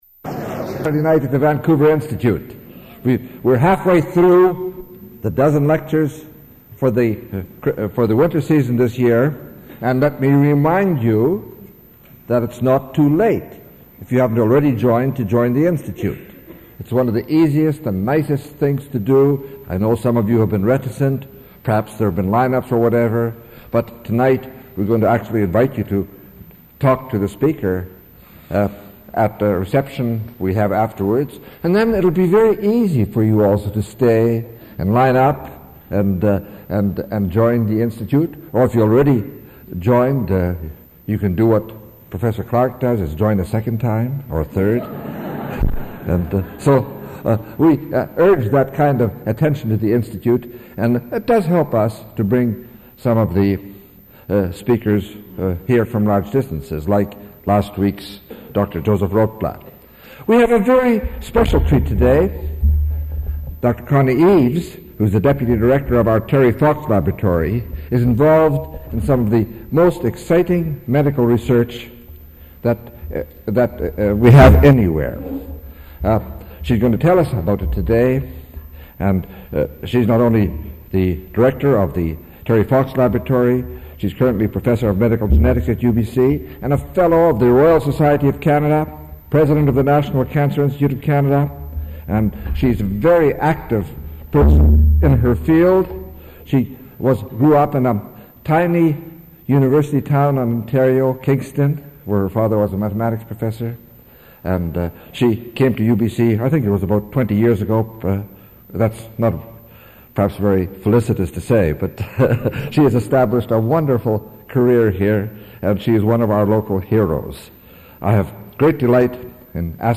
Original audio recording available in the University Archives (UBC AT 2010).